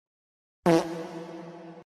Le S (Prout)
Myinstants > 사운드 > Sound Effects > Le S
le-s-prout.mp3